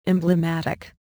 Click here to hear a pronunciation of emblematic.